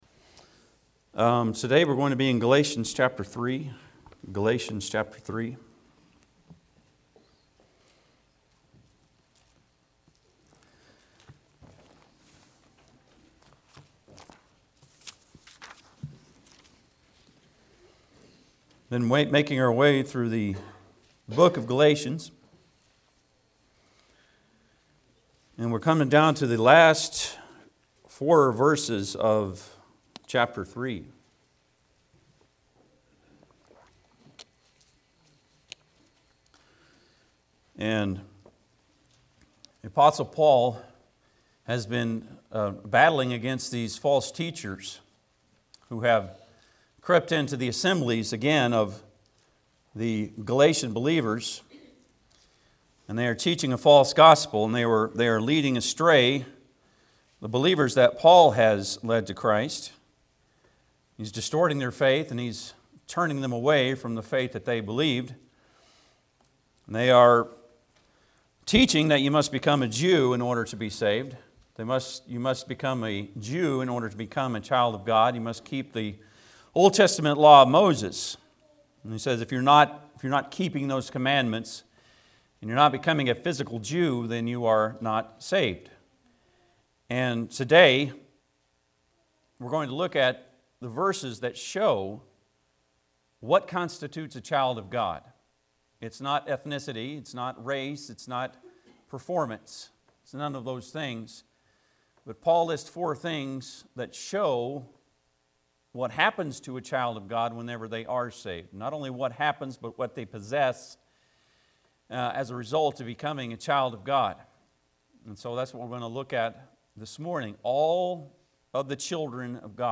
Service Type: Sunday am